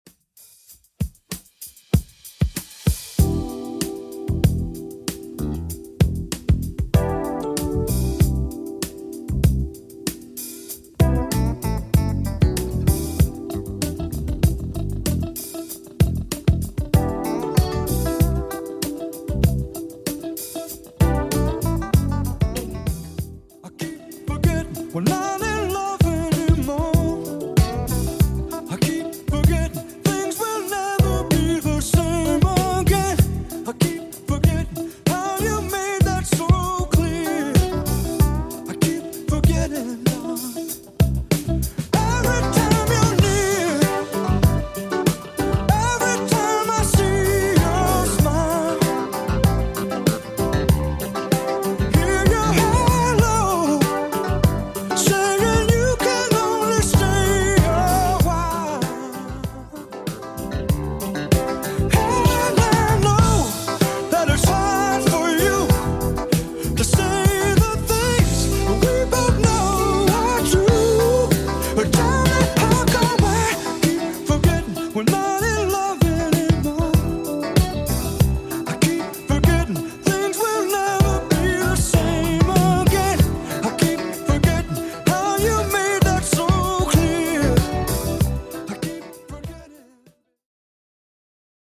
80s Xtnd Intro) 96bpm CLEAN